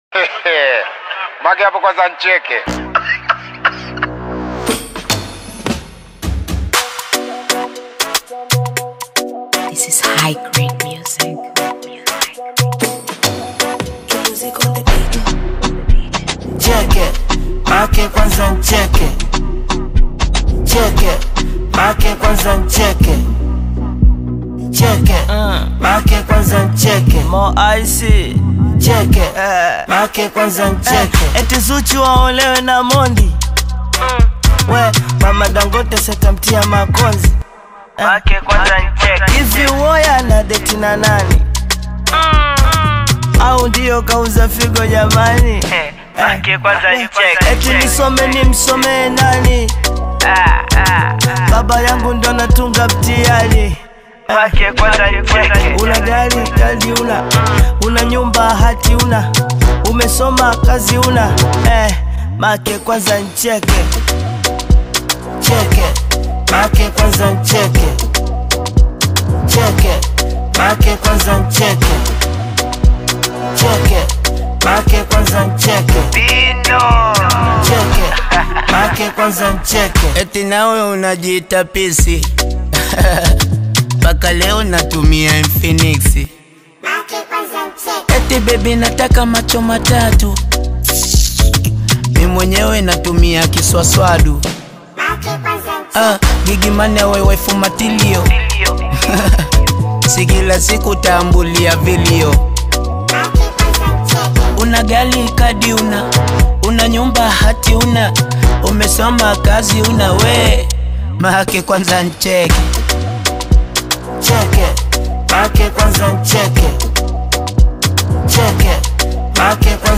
African Music song